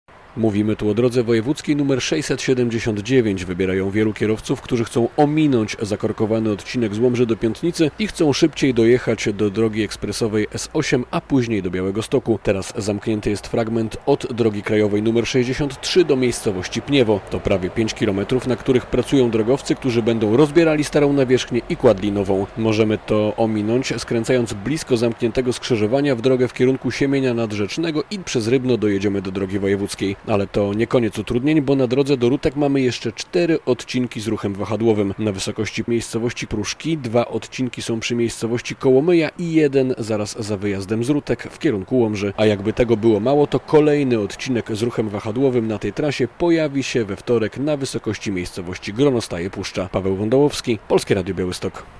Zamknięty odcinek drogi z Łomży do Mężenina - relacja